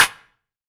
SNARE 116.wav